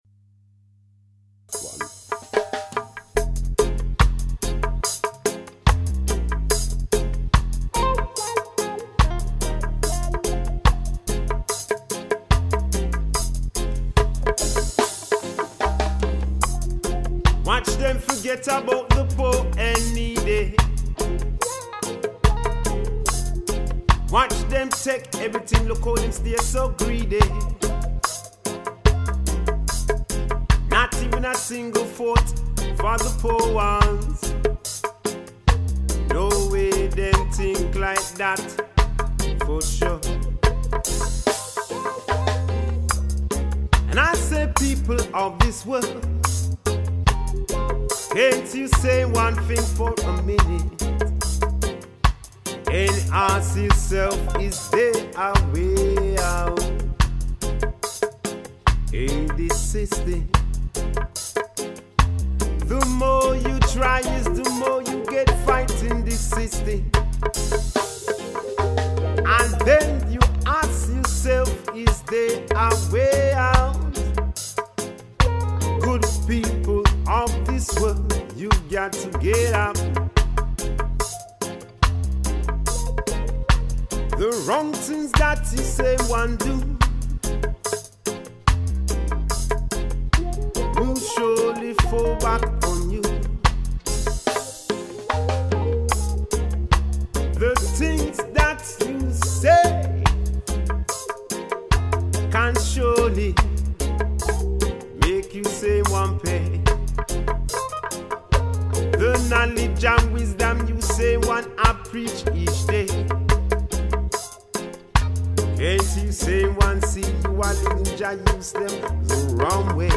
guitars recorded @ hall place studio lds uk 2010